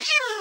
assets / minecraft / sounds / mob / cat / hitt3.ogg
hitt3.ogg